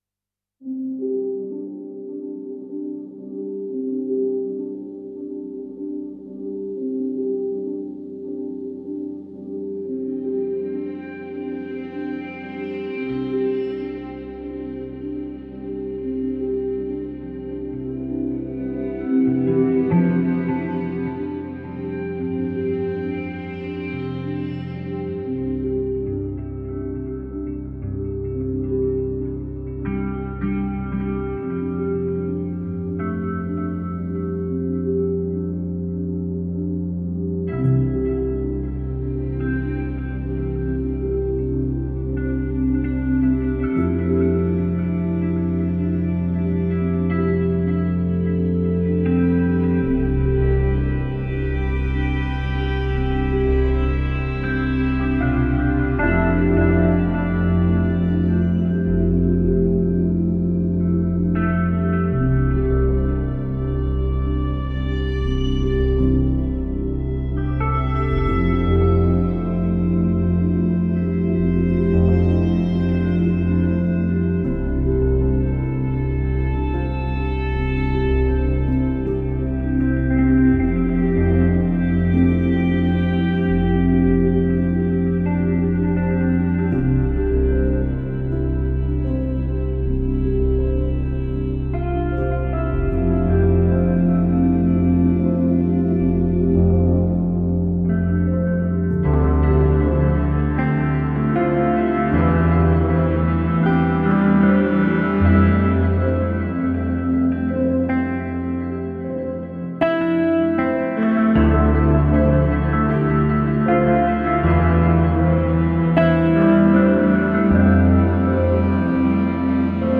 Textural layers, tones and natural atmosphere.